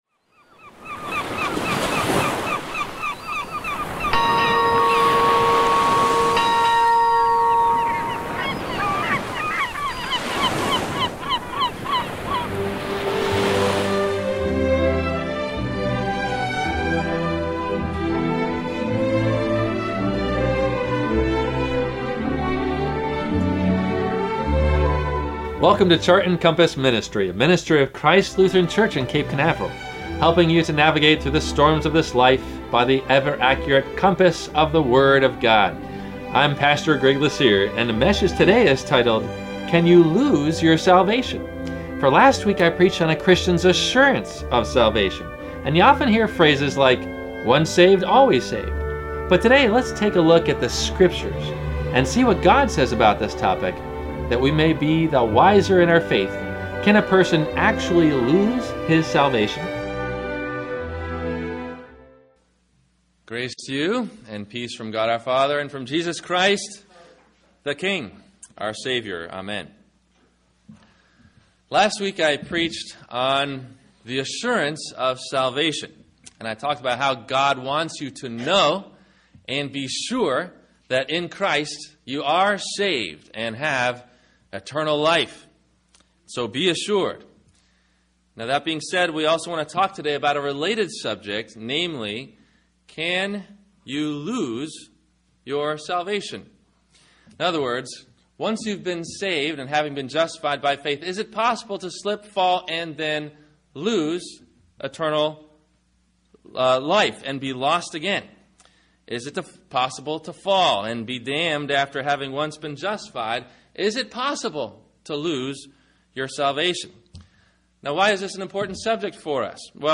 Can You Lose Your Salvation? – WMIE Radio Sermon – October 06 2014